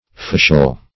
Meaning of fecial. fecial synonyms, pronunciation, spelling and more from Free Dictionary.